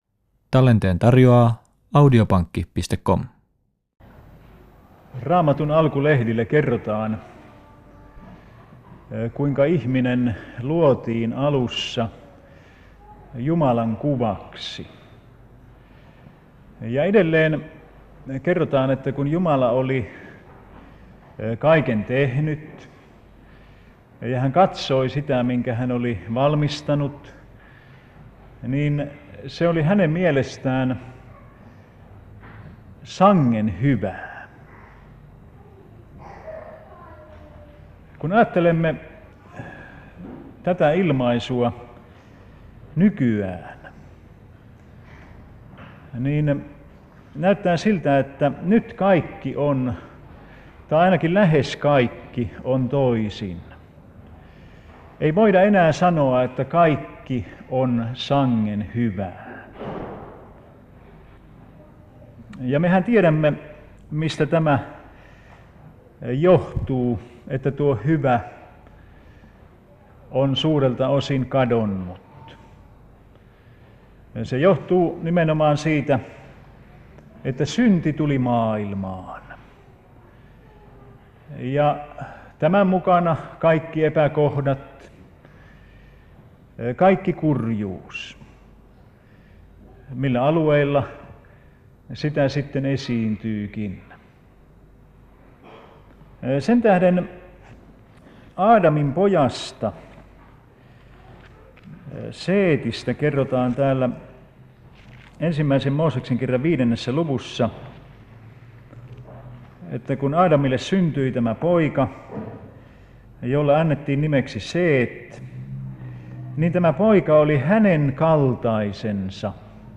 Tampereella 7.11.1981 https